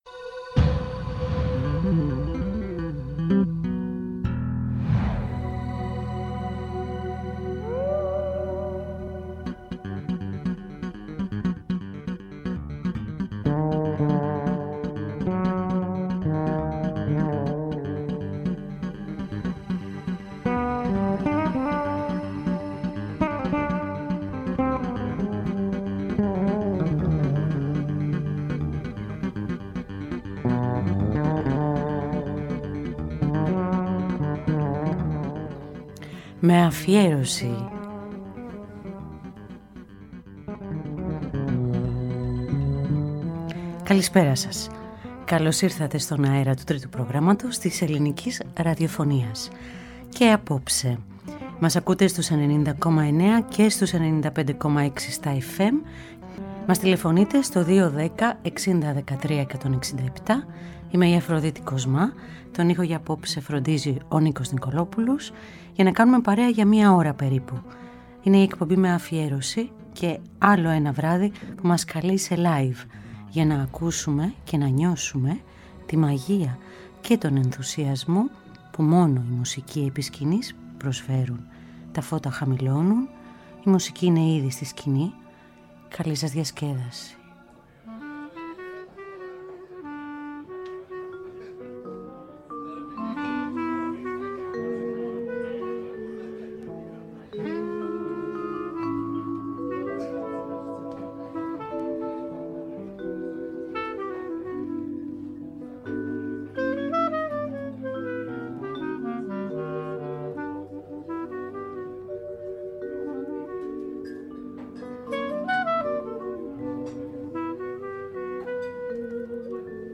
Live at … Η νύχτα διψάει για live, ελάτε, κατεβείτε τα σκαλιά, πάρτε το εισιτήριό σας, μπείτε παρέα με όλες τις διαθέσεις σας, διαλέξτε θέση, βολευτείτε, παραγγείλετε το ποτό σας, τα φώτα χαμηλώνουν, οι μουσικοί είναι ήδη στη σκηνή….
jazz